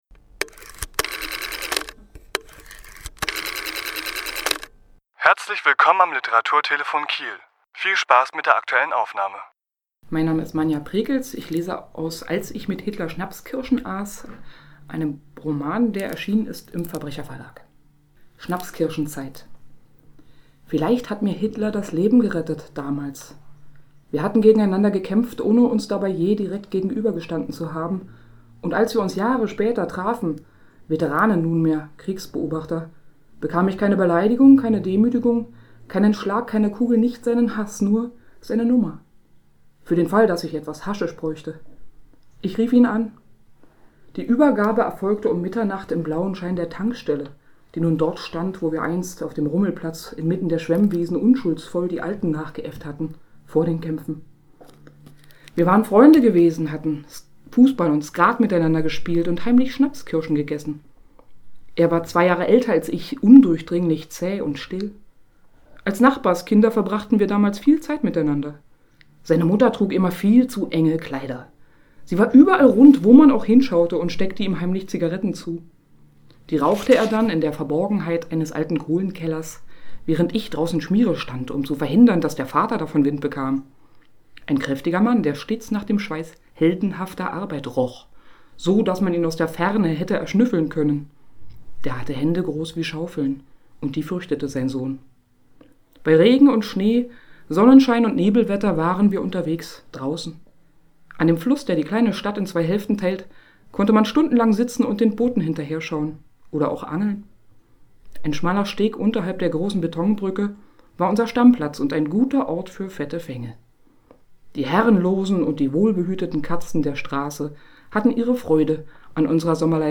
Autor*innen lesen aus ihren Werken
Die Aufnahme entstand im Rahmen einer Lesung in der Reihe Leselounge im Literaturhaus Schleswig-Holstein am 7.2.2018.